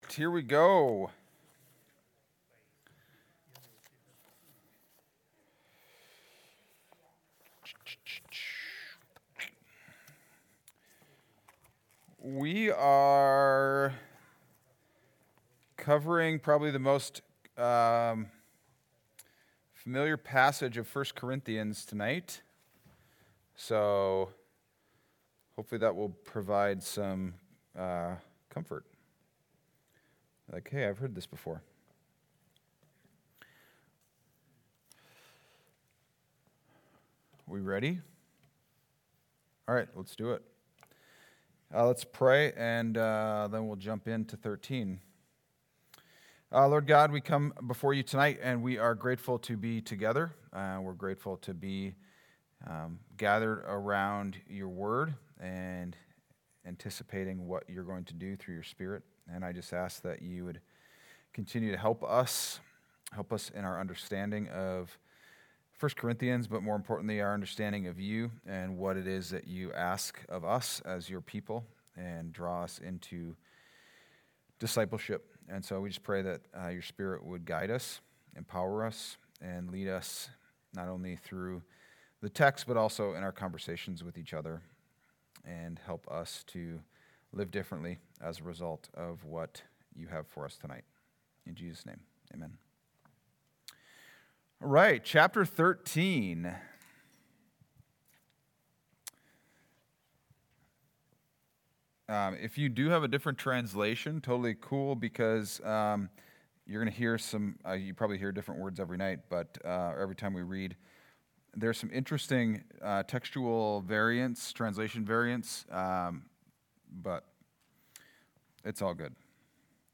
Wednesday Adult Study: 1-21-26